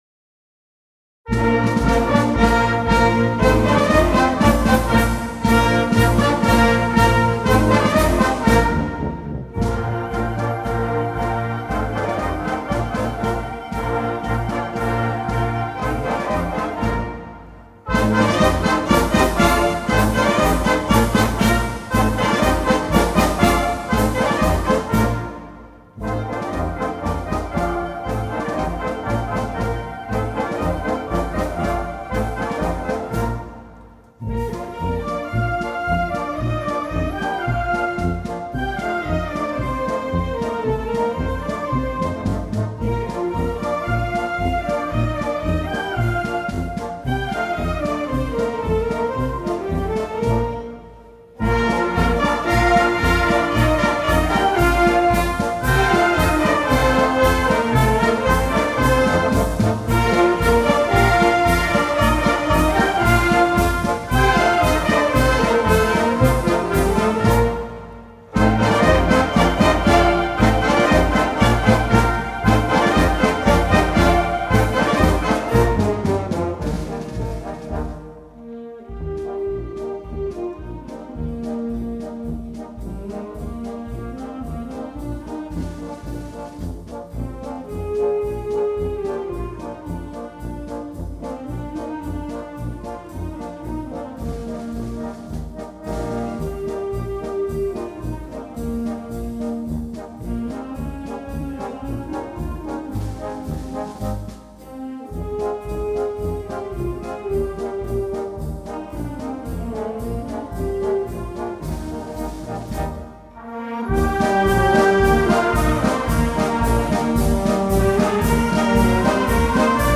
jon�s diana (Eugenio G�mez) Descargar partituras Escuchar la goya diana (Eugenio G�mez) Descargar partituras Escuchar despierta susana diana (eugenio g�mez) Descargar partituras Escuchar nuevas dianas